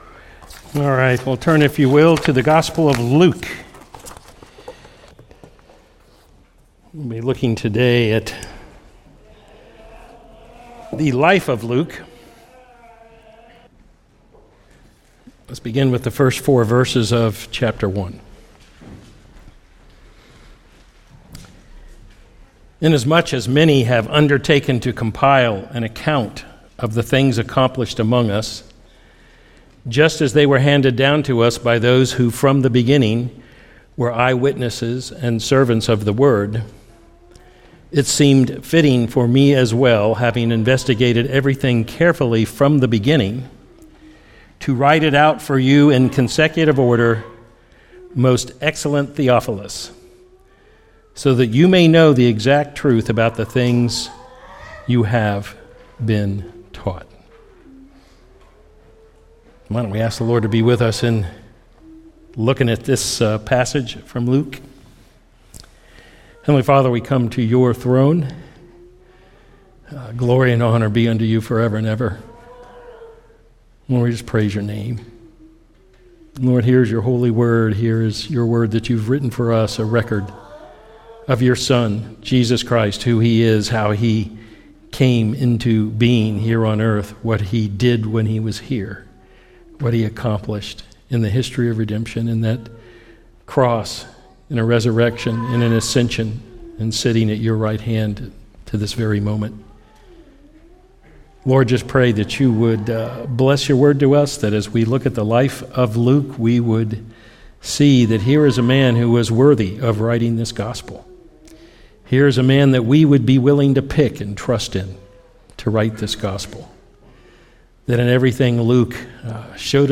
Luke 1: Who Was Luke? Sermons podcast